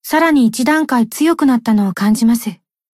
贡献 ） 分类:蔚蓝档案语音 协议:Copyright 您不可以覆盖此文件。
BA_V_Sumire_Tactic_Victory_2.ogg